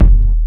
Kick (I Wonder).wav